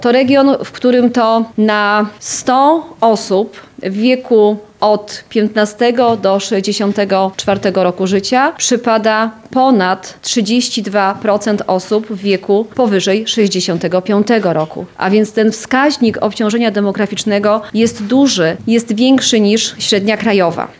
– Lubelszczyzna to region, który będzie się najszybciej starzał – mówi ministra ds. polityki senioralnej Marzena Okła-Drewnowicz.